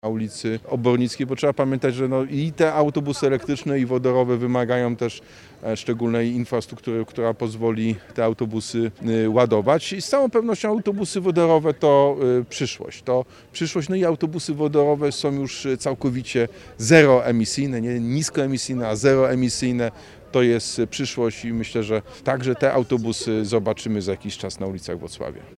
– Przygotowujemy się do inwestycji związanej z budową stacji ładowania wodorowego – zaznaczył Jacek Sutryk.